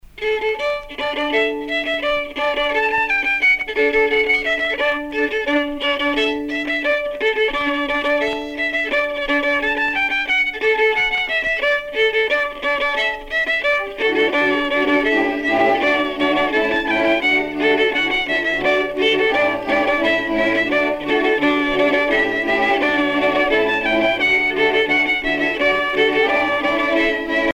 danse : pas d'été ;
Pièce musicale éditée